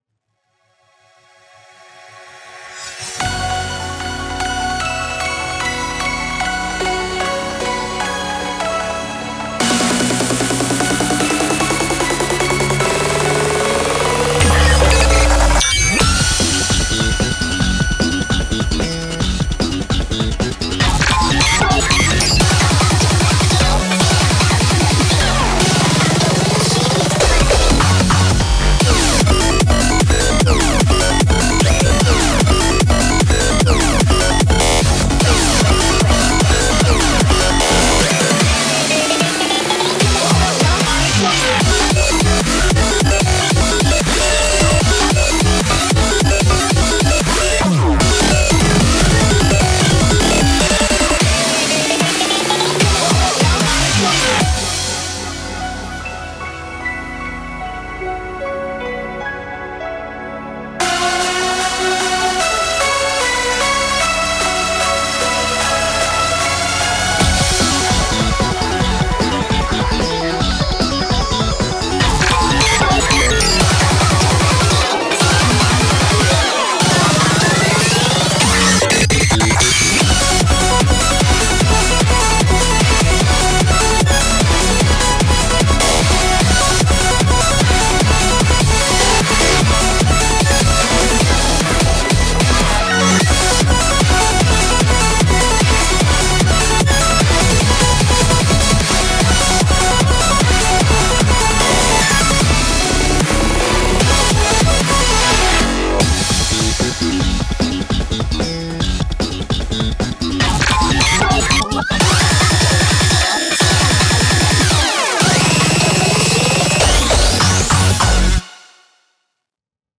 BPM150
Audio QualityPerfect (High Quality)